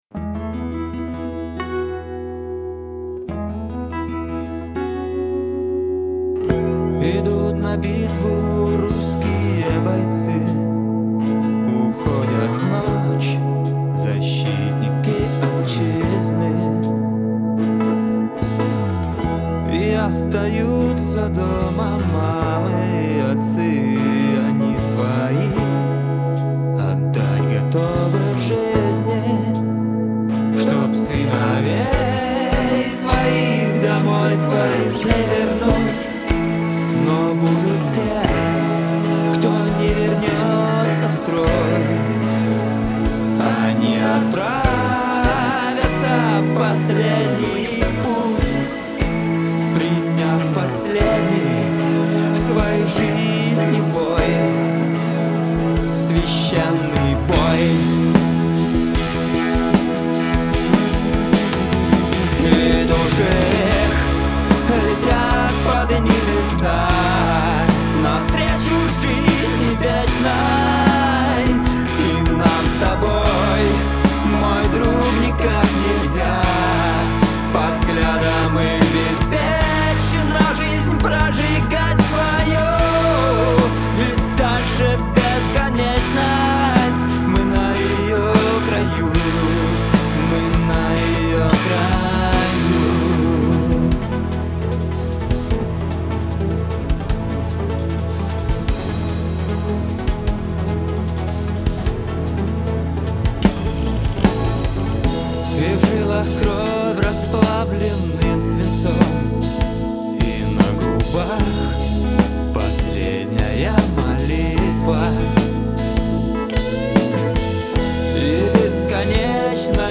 Стихи, музыка, гитары, бас, программирование барабанов, клавишные, вокалы, запись...